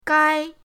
gai1.mp3